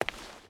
Footsteps
Stone Walk 5.wav